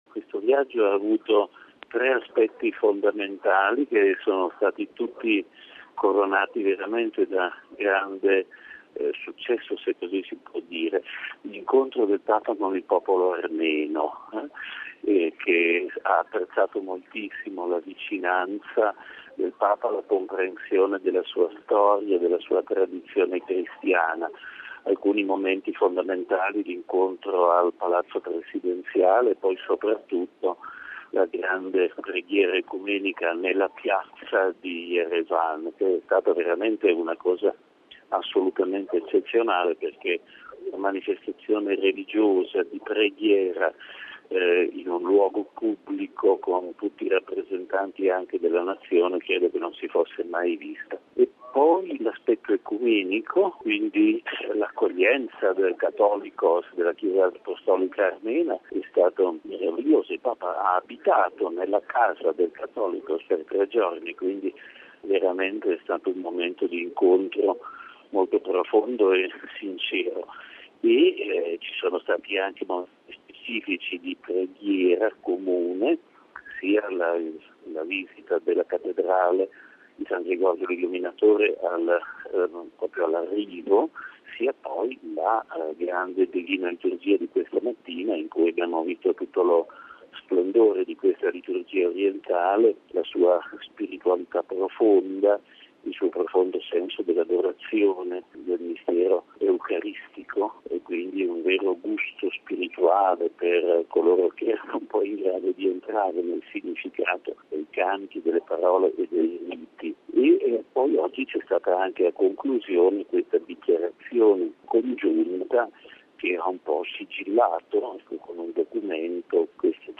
A conclusione del viaggio del Papa in Armenia ascoltiamo il commento del direttore della Sala Stampa vaticana, padre Federico Lombardi